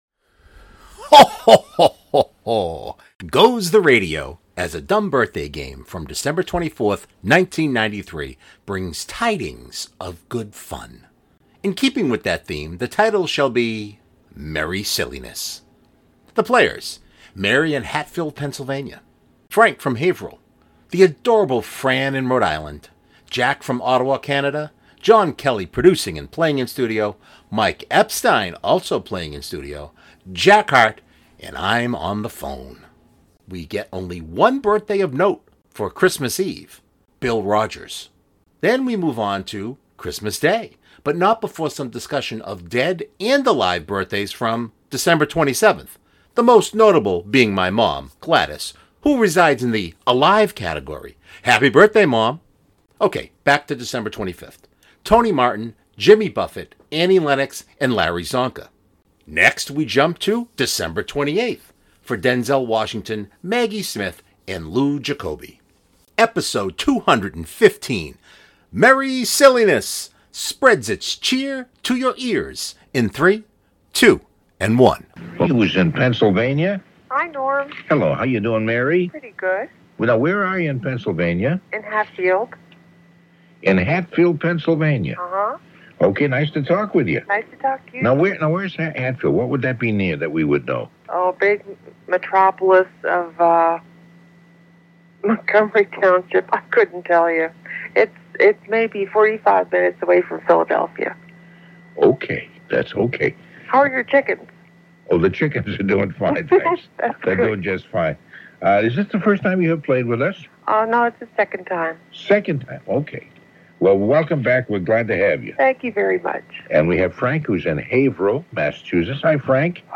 Ho Ho Ho goes the radio as a DBG from December 24th, 1993 brings tidings of good fun.